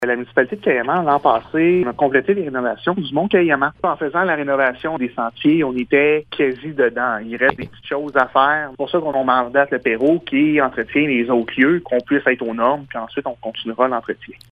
Le maire de Cayamant, Nicolas Malette, explique :